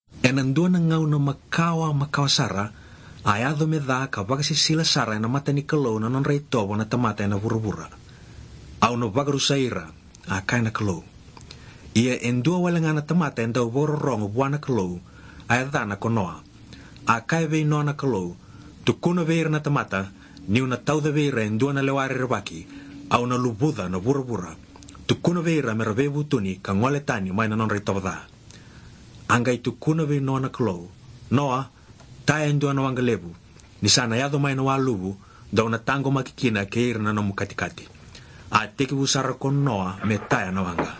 Interesting to hear the almost Spanish-sounding lilt and frequent use of the alveolar trill.